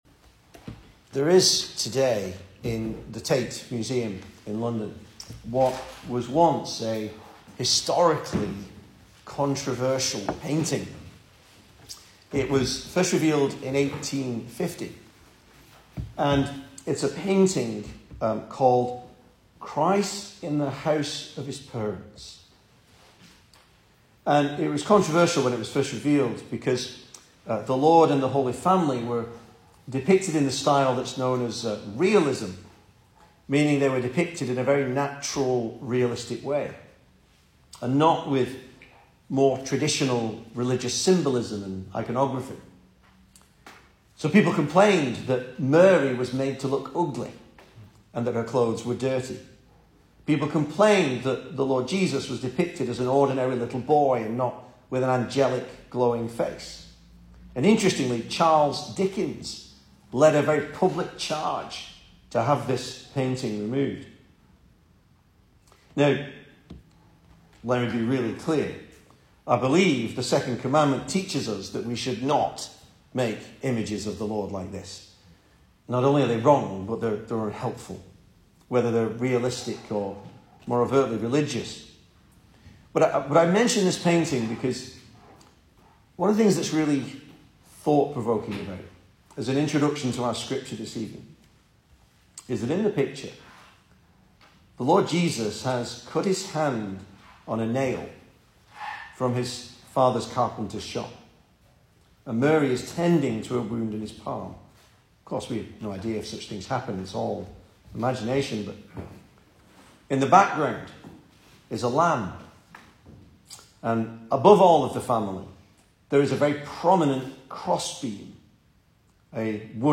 Service Type: Sunday Evening
Christmas Sermons